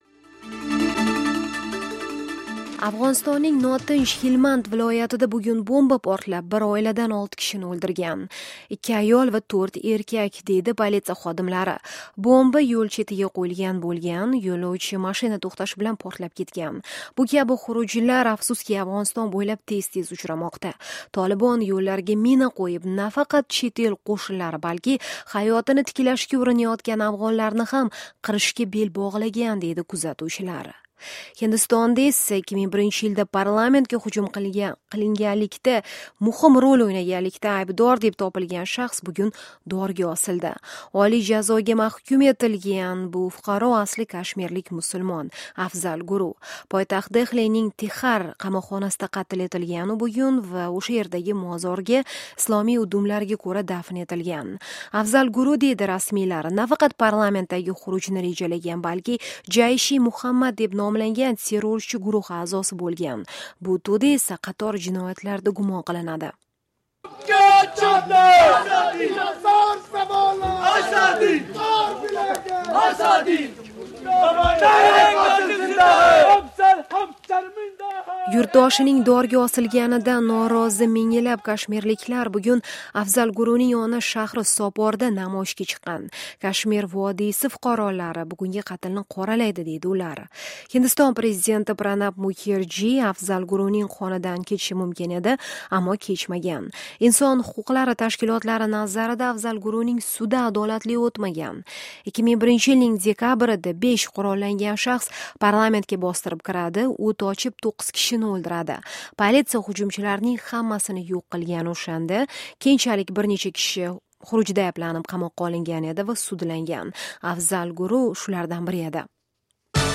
"Amerika Ovozi" xabarlari, 9-fevral, 2013